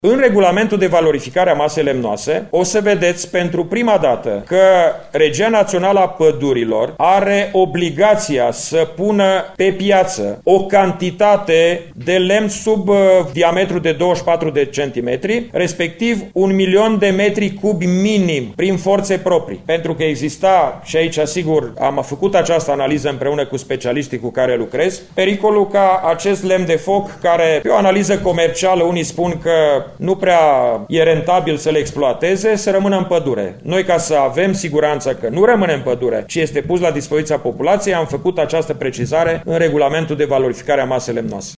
Ministrul spune că, pentru prima dată, Regia Națională a Pădurilor este obligată să pună pe piață un milion de metri cubi de lemn de foc pentru populație: